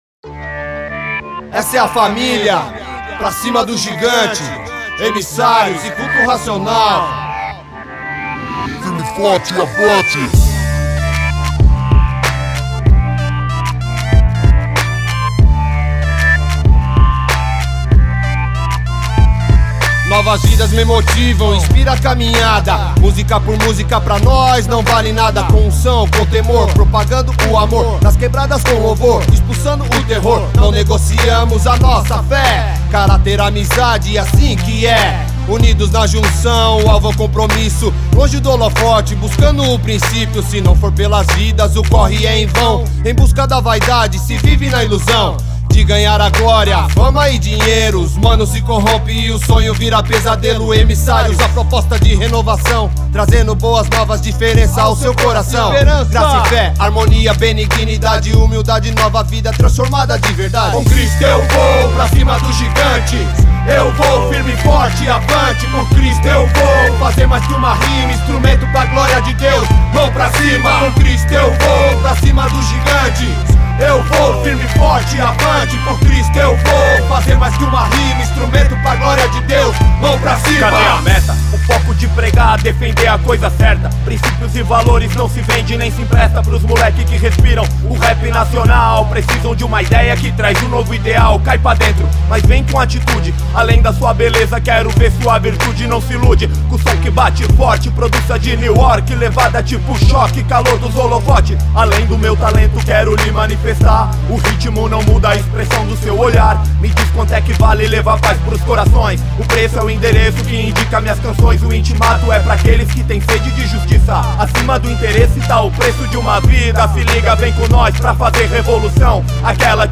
grupo de rap